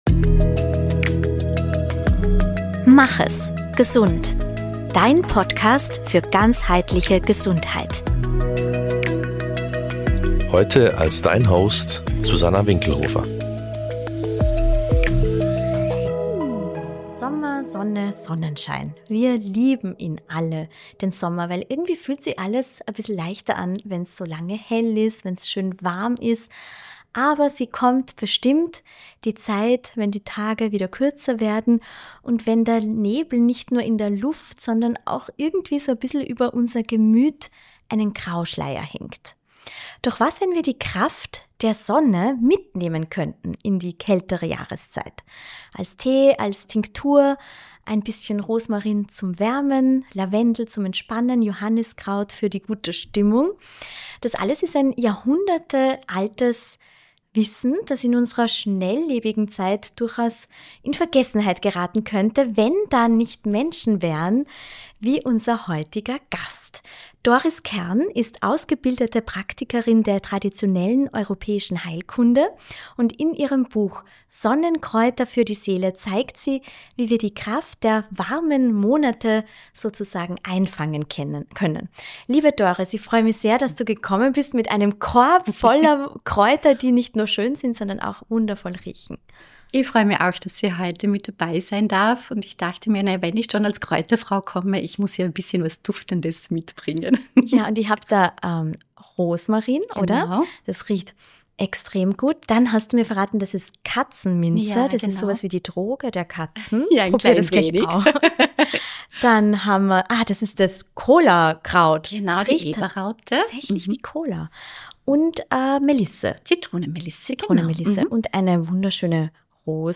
Ein Gespräch über duftende Rosen, belebenden Rosmarintee am Morgen und das stille Glück, wenn eine selbstgemachte Salbe gelingt.